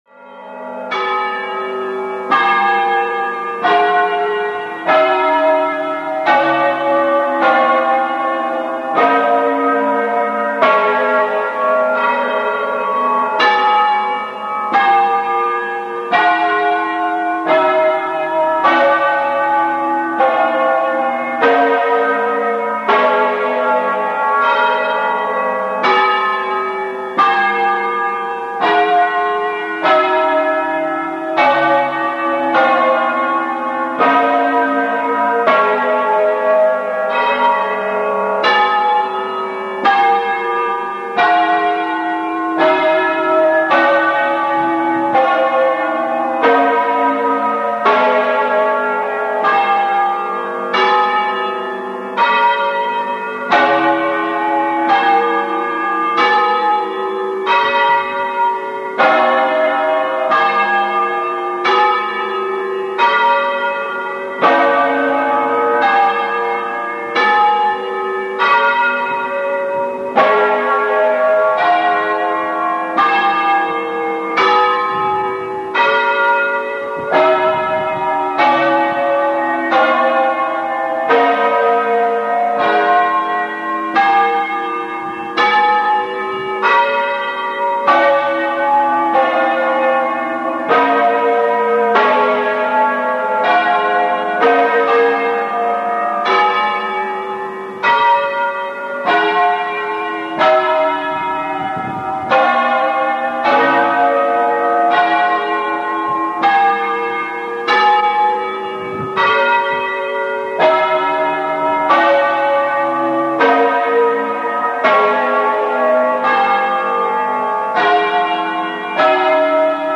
In the tall (79.4m) tower, which was built between 1913 and 1925, is a ring of nine bells, tenor 90cwt (in Ab), making them the heaviest full circle ring in the world.
For a 1.6Mb MP3 recording of a longer part of a concerto (with the 2003 tenor), click